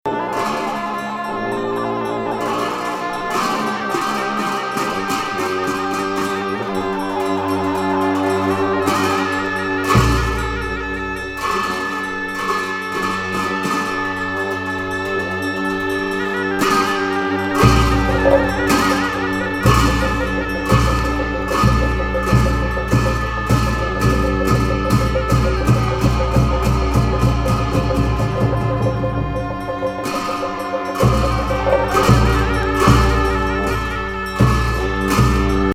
スピトク寺護法尊供養祭のフィールド・レコーディング(?) 宗教全開、アヤしいお経と鐘と鳴り物いろいろ。